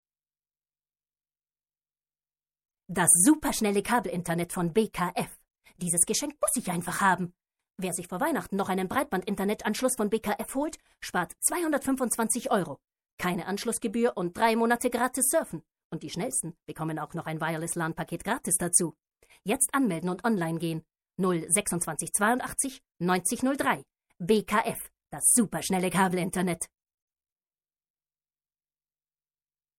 Vielseitige Schauspielerin und SĂ€ngerin mit klarer, charaktervoller, direkter Sprache - Mezzosopran.
Sprechprobe: Werbung (Muttersprache):